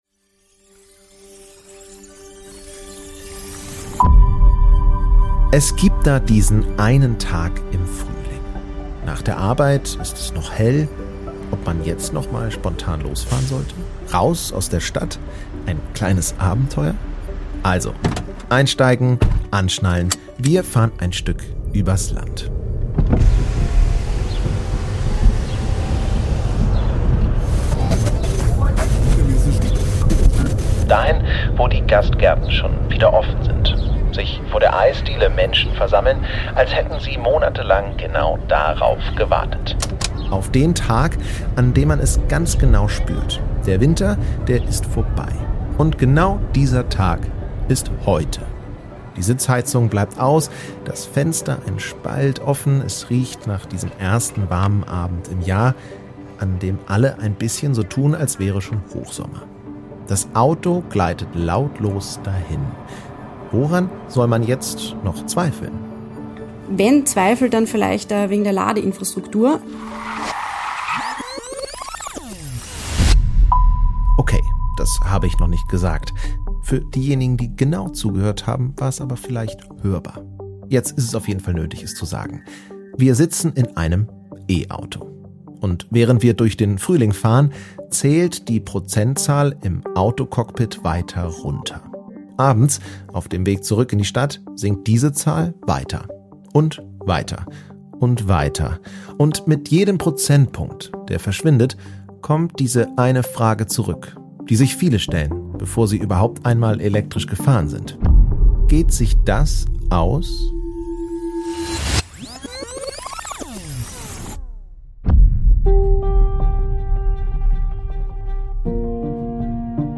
Eine E-Auto-Fahrerin berichtet aus dem Alltag: Was verändert sich beim Umstieg wirklich?